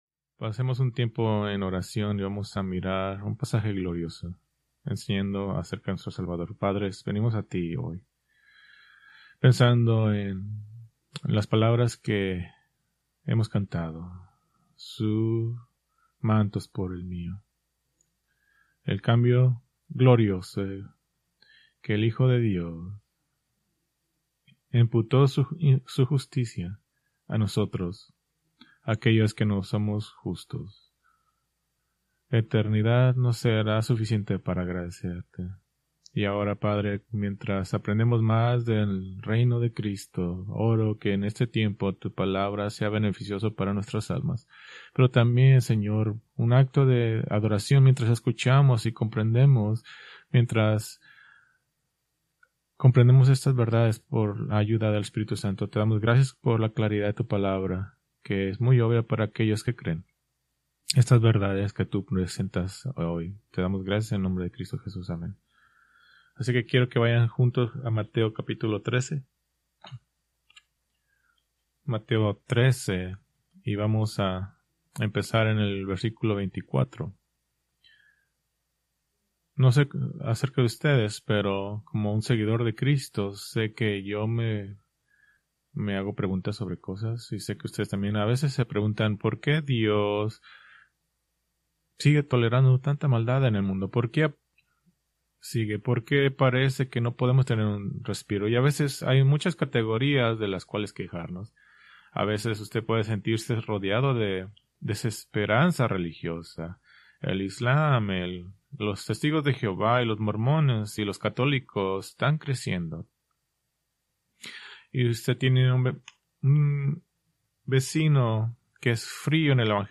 Preached February 15, 2026 from Mateo 13:24-30, 36-43